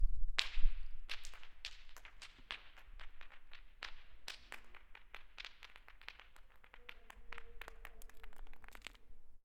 stone tumbling towards you in a cavern
24bit 48000 canyon cavern fall falling field-recording rock sound effect free sound royalty free Sound Effects